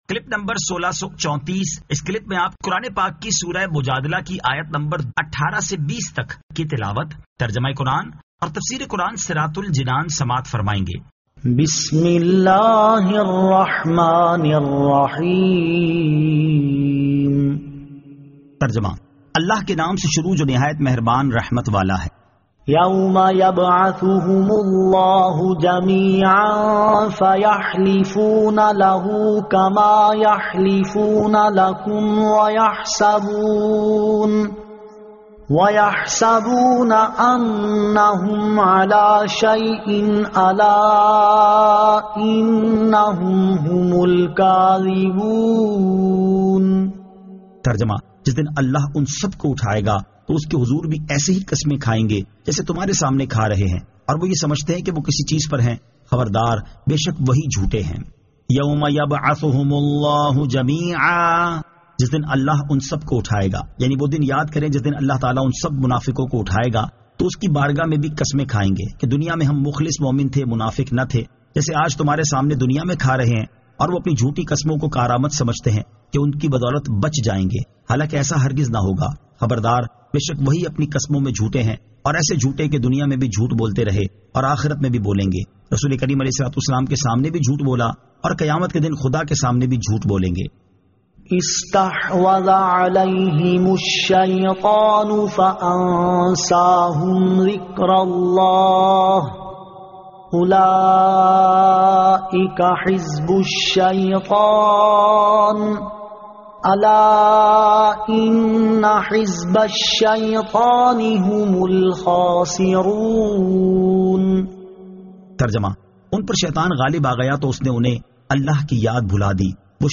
Surah Al-Mujadila 18 To 20 Tilawat , Tarjama , Tafseer